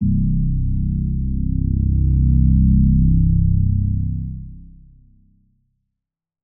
808s
(C) soft reese 808.wav